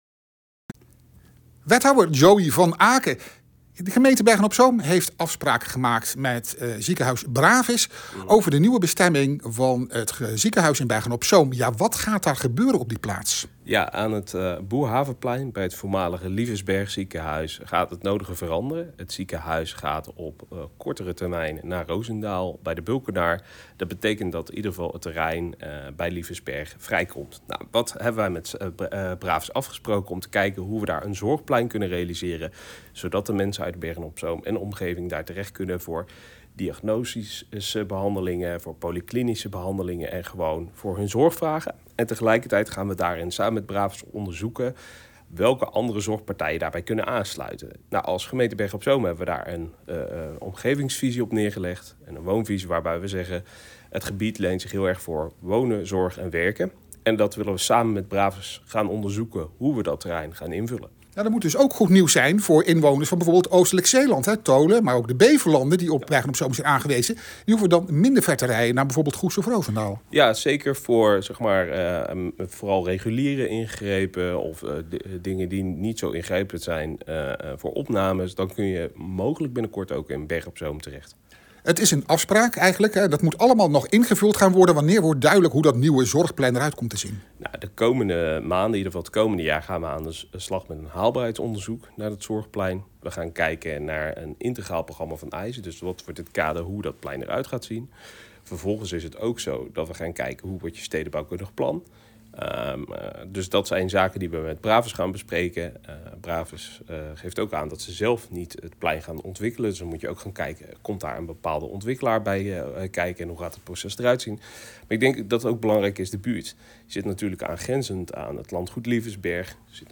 Audio: Wethouder Joey van Aken heeft goede hoop, dat het zorgplein van de grond komt.